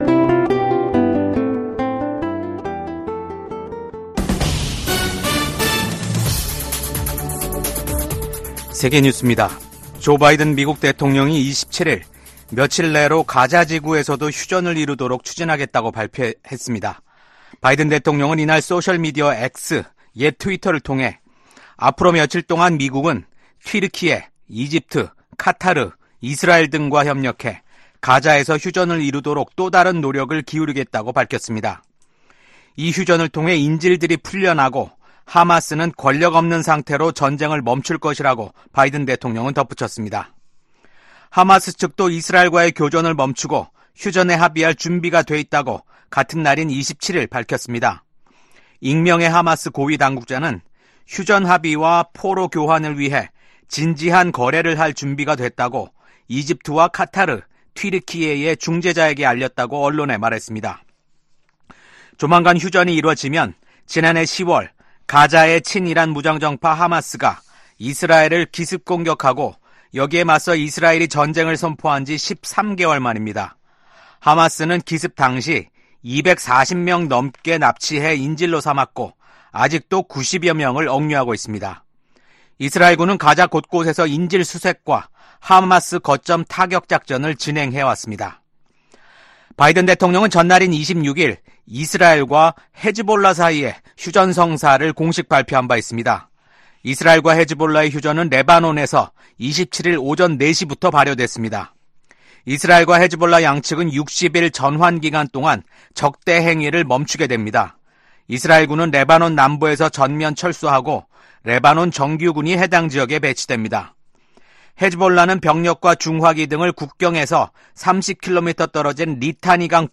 VOA 한국어 아침 뉴스 프로그램 '워싱턴 뉴스 광장'입니다. 미국은 북한군이 현재 우크라이나로 진격하지는 않았다고 밝혔습니다. 러시아가 북한에 파병 대가로 이중용도 기술과 장비를 판매하고 있다고 미 국무부가 밝혔습니다.